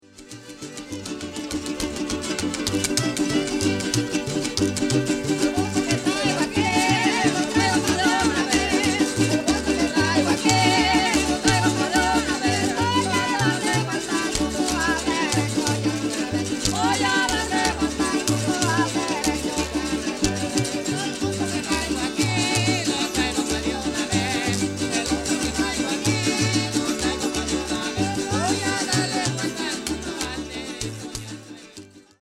Mexican Mariachi